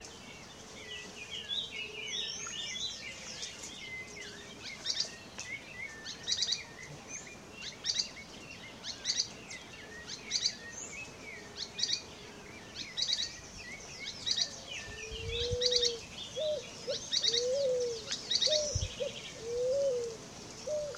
Spix´s Spinetail (Synallaxis spixi)
Spanish Name: Pijuí Plomizo
Location or protected area: Reserva Privada El Potrero de San Lorenzo, Gualeguaychú
Condition: Wild
Certainty: Observed, Recorded vocal